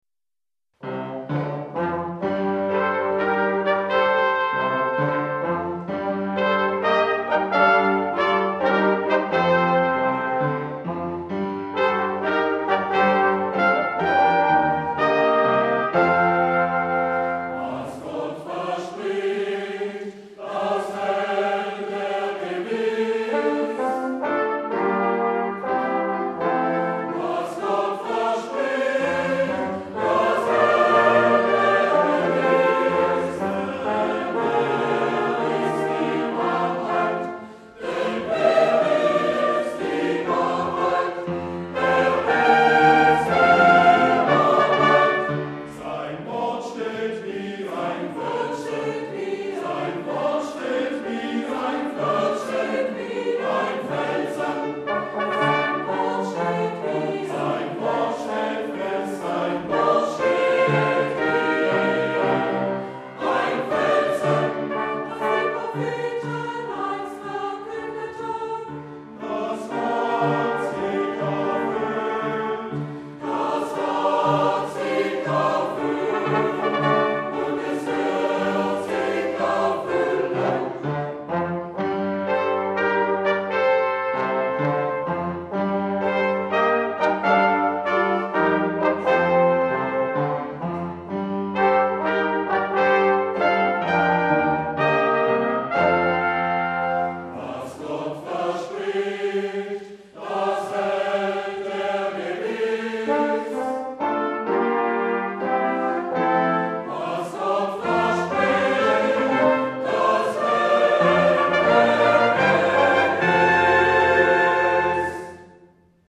Vorweihnachtliches Singen und Musizieren
Mit beschaulichen Impulsen begann vom Frohsinn Rot in der Adventskantate Machet die Tore weit von Klaus Heizmann.
Trompete
Posaune
Klarinette
Querflöte
Klavier